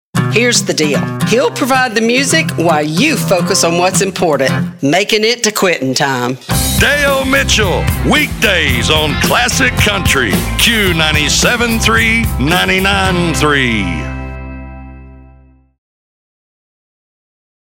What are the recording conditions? full production FacebookPinterestTwitterLinkedin